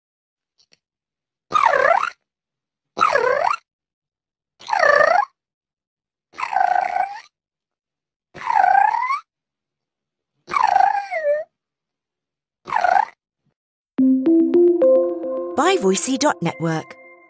Scp-999 - Gurgles